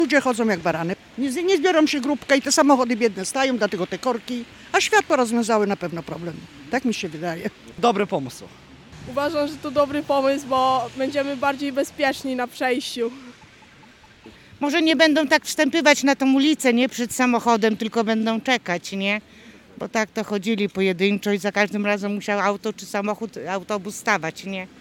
STAR-Mieszkancy-Czarnieckiego.mp3